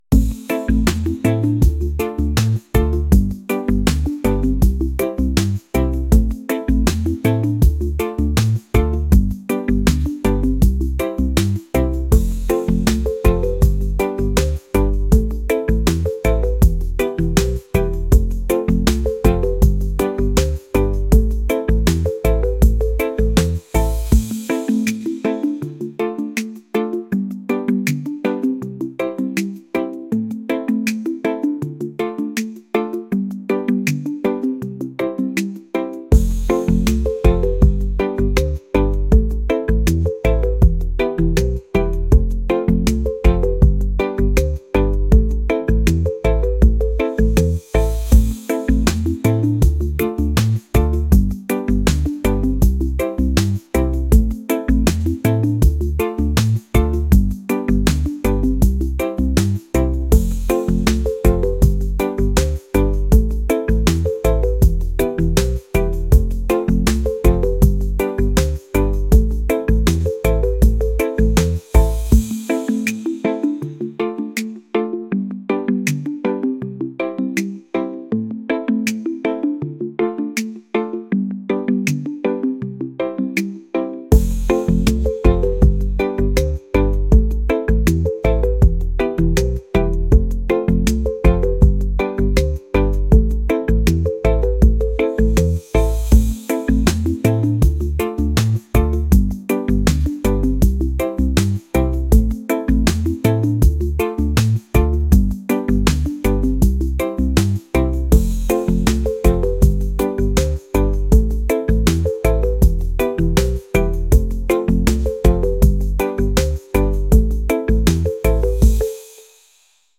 reggae | pop | lofi & chill beats